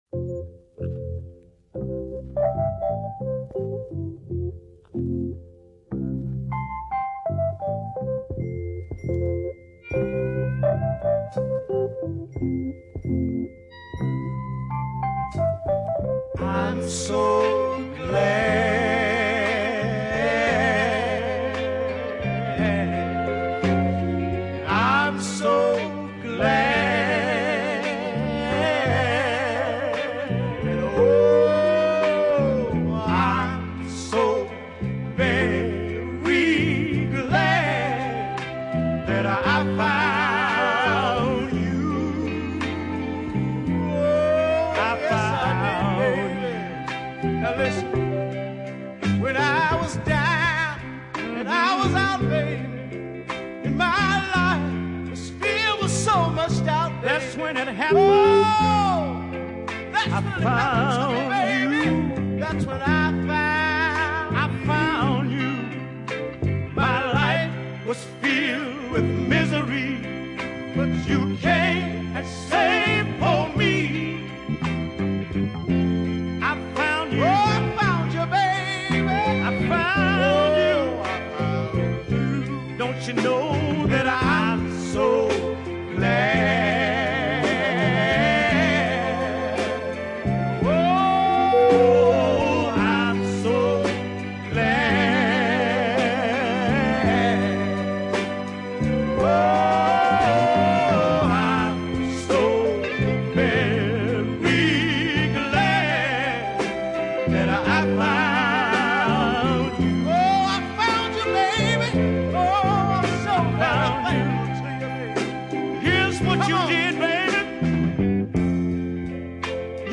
another strong, melodic slowie